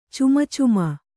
♪ cumacuma